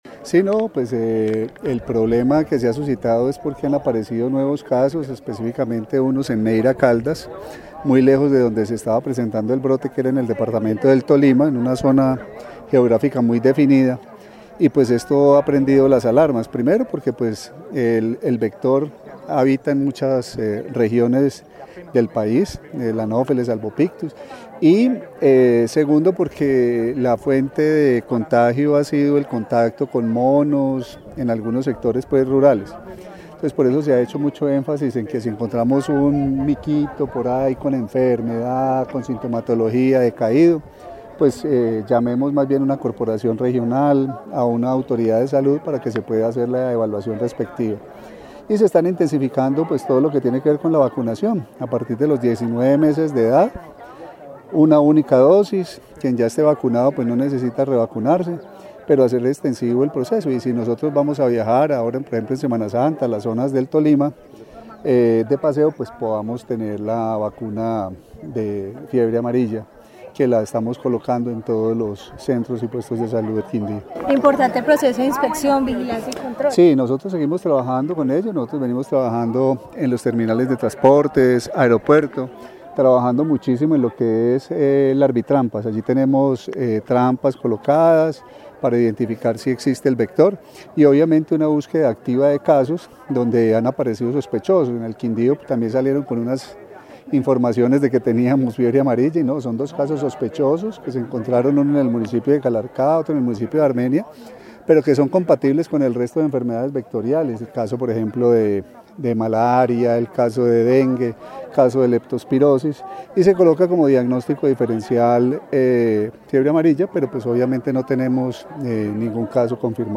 Secretario de Salud del Quindío, Carlos Gómez